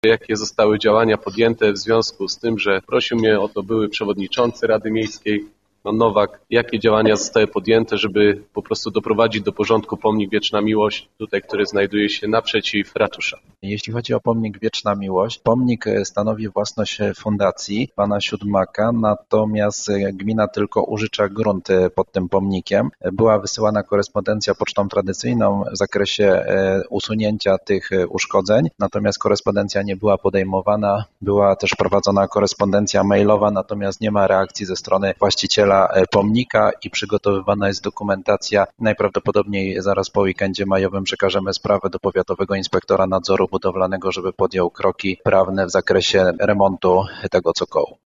Jakie działania zostały podjęte, aby doprowadzić do porządku pomnik Wieczna Miłość? – pytał dziś na sesji Rady Miejskiej w Wieluniu radny Wojciech Psuja.